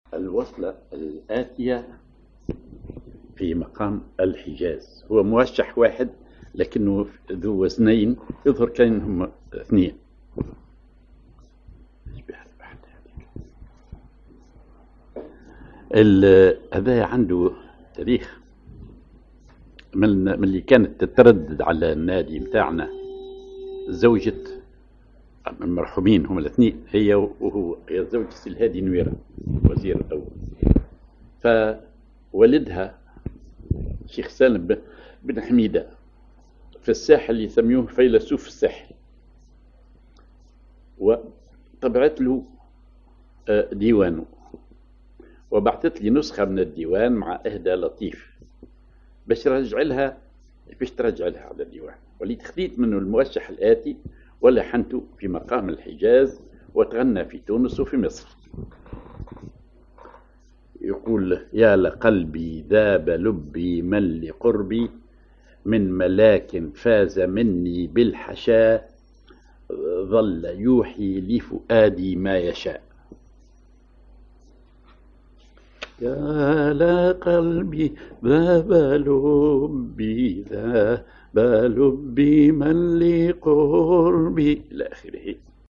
Maqam ar حجاز
Rhythm ar سماعي ثقيل/ دور هندي
genre موشح